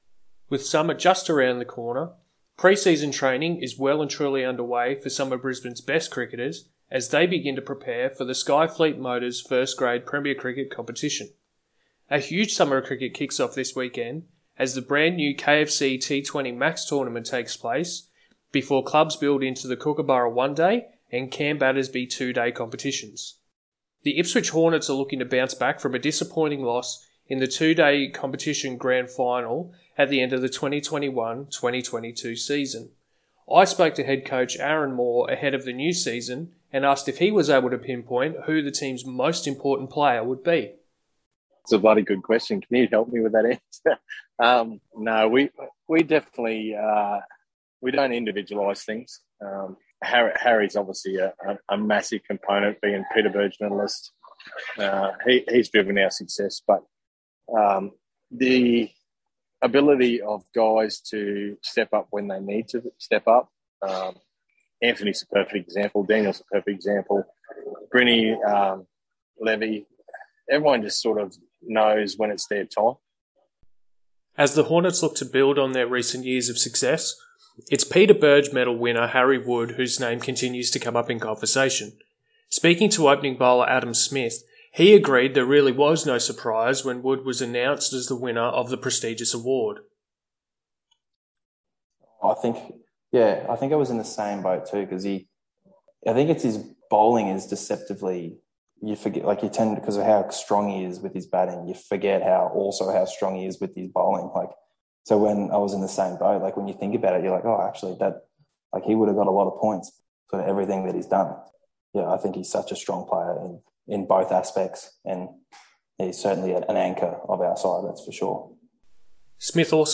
first grade player